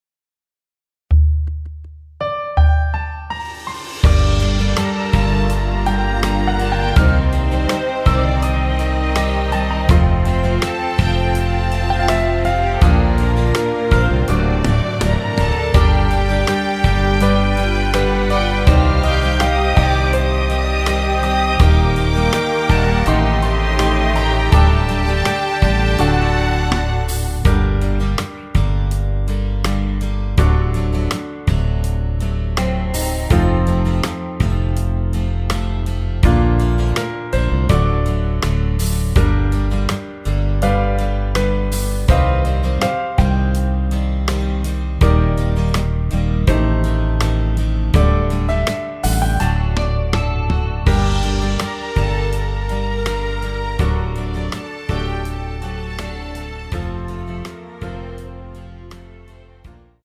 원키에서(+5)올린 MR입니다.
Gm
앞부분30초, 뒷부분30초씩 편집해서 올려 드리고 있습니다.